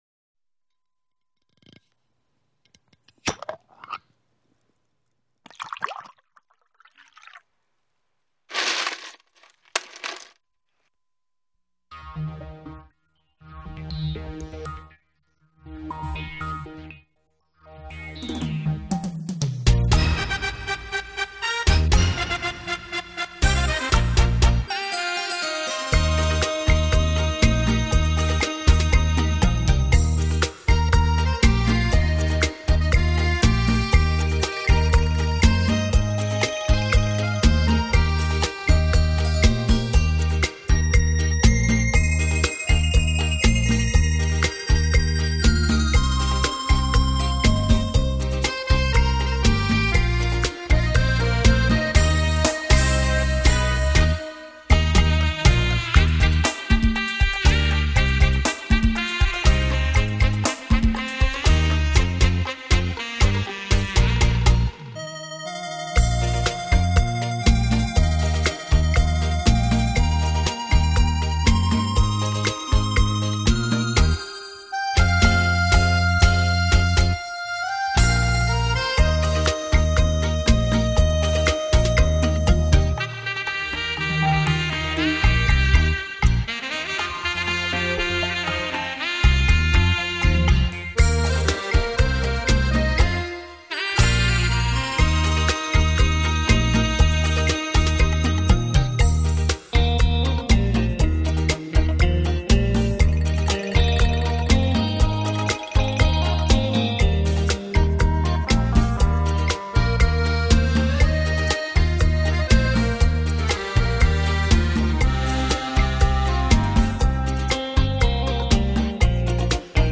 128K低音质MP3